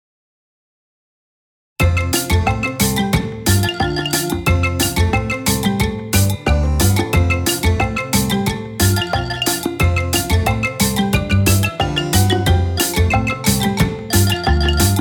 Anime, Kids